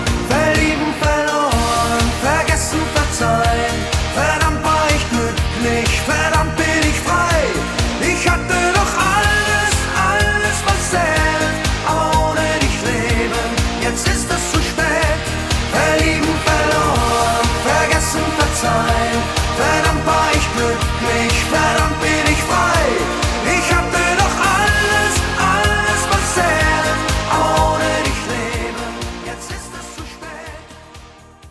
Gattung: Moderner Einzeltitel mit Gesang ad. Lib
Besetzung: Blasorchester
und fetzigen Arrangement